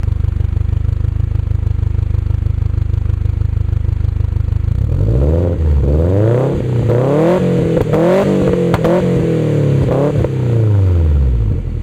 アイドリング音は、ボッボッボッよりベッベッベッの方が近いか？
アイドリング→レーシング おっかなびっくりバージョン 592KB
音は、FLATエキマニType3と、RM-01A Tiの組み合せです。